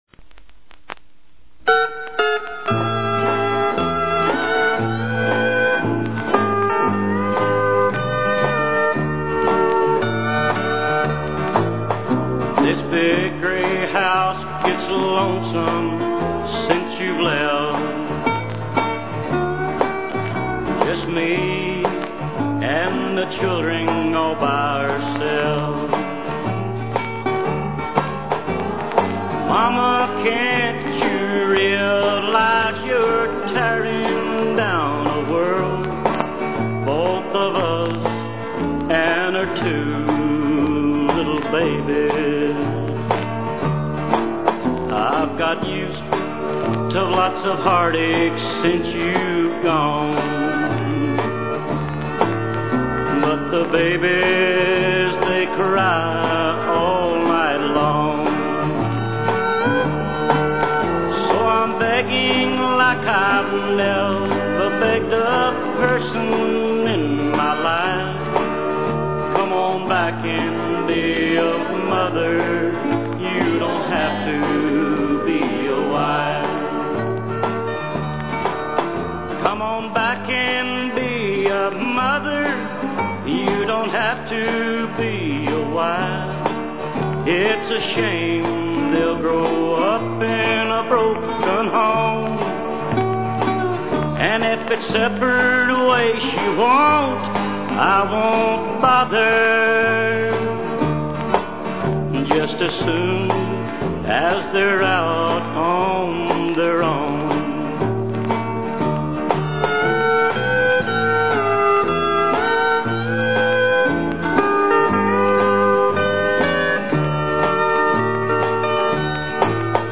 A Tribute To Old Time Country Music